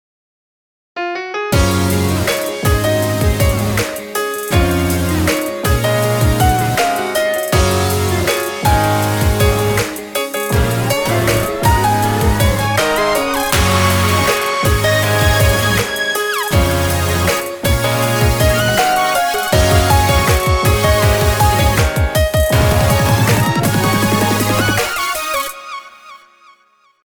Kawaii Future Bass習作